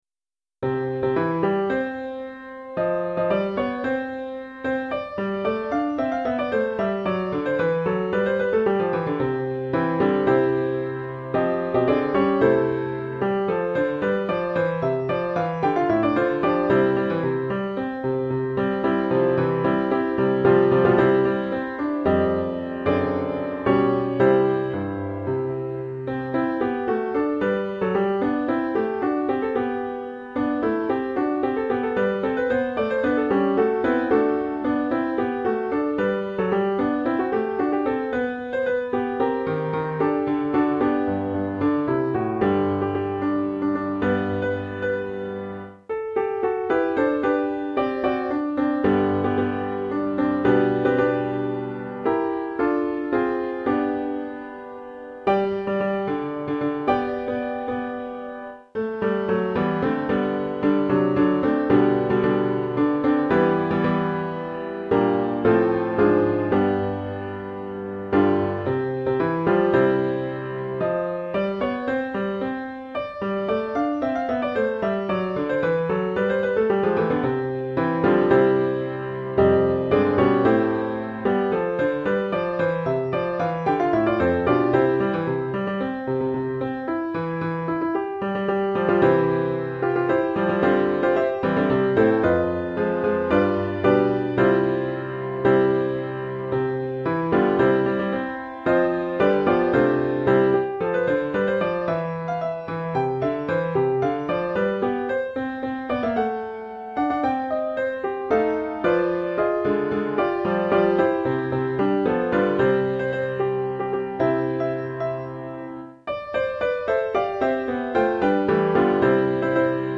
It's a setting of Psalm 99, Adonai Malach Yirgezu Amim, as part of my setting of Kabbalat Shabbat, the introductory Psalms for the Friday night liturgy. As usual, I have posted a computer-generated